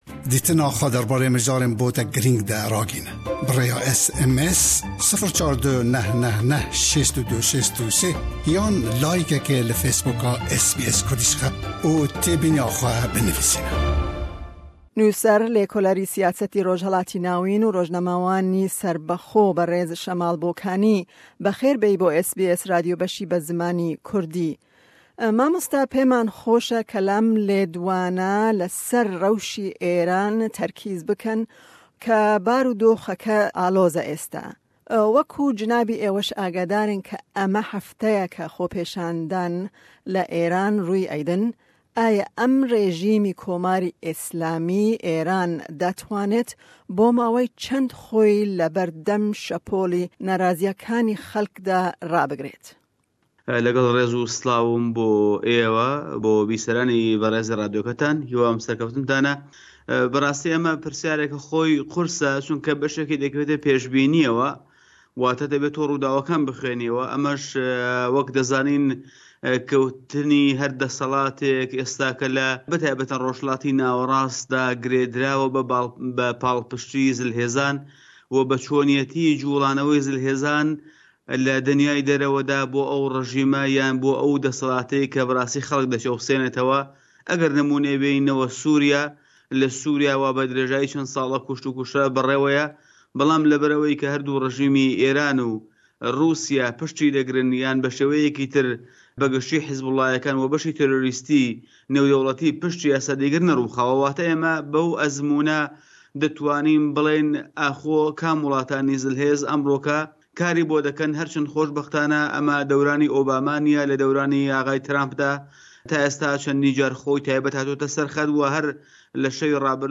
Em derbarê rewsha li Êranê û ji bo chi Kurdên Rojhilat xwe têkele xwepêshandanan nakin bi pisporê Rojhilata Navîn, nivîskar, lêkoler û rojnamevanê serbixwe